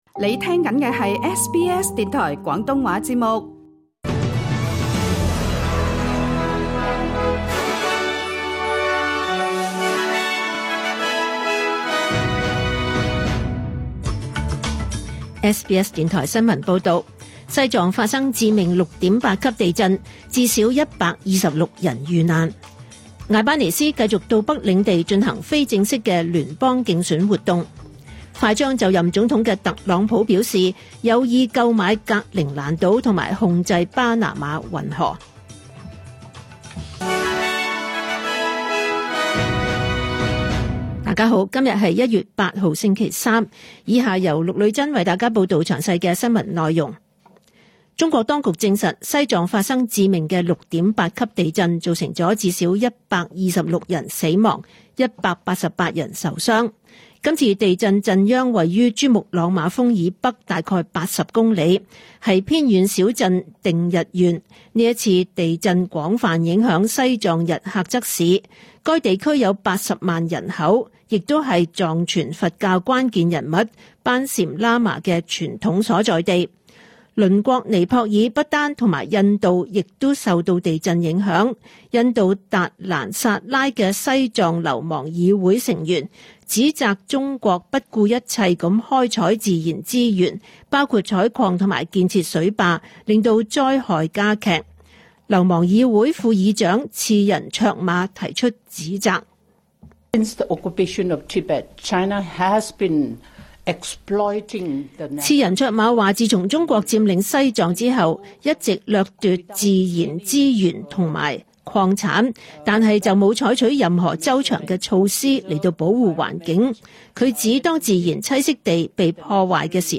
2025 年 1 月 8 日 SBS 廣東話節目詳盡早晨新聞報道。